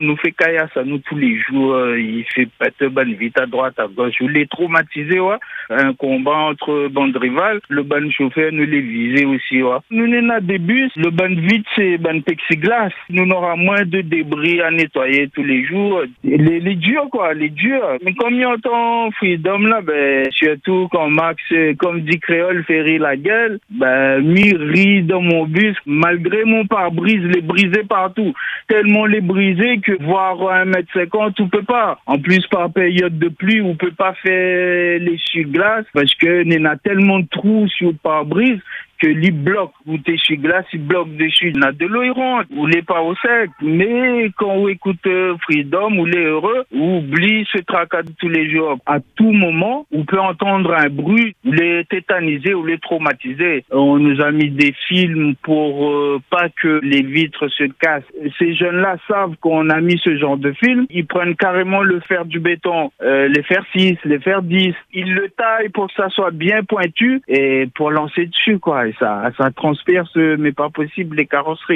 À Mayotte, le quotidien de certains chauffeurs de bus est devenu tout simplement invivable. Ancien conducteur à La Réunion, cet homme nous livre un témoignage glaçant sur la réalité qu’il affronte aujourd’hui sur les routes mahoraises.